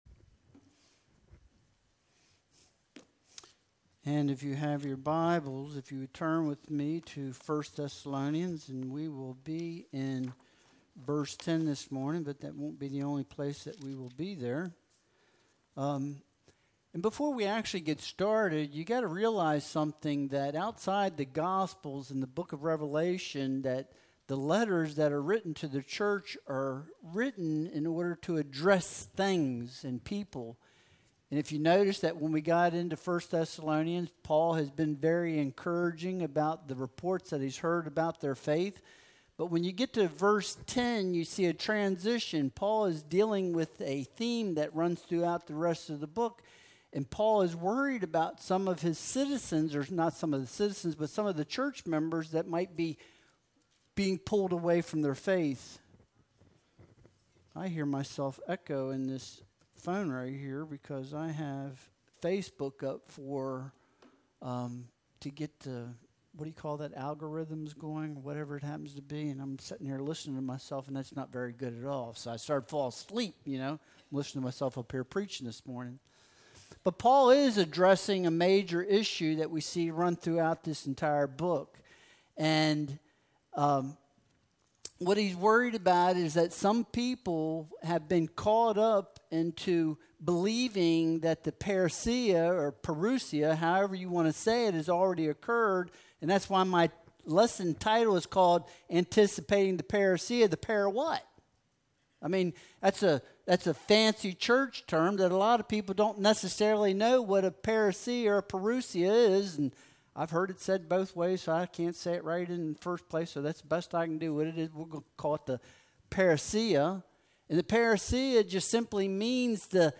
1 Thessalonians 1.10 Service Type: Sunday Worship Service Download Files Bulletin « A True Servant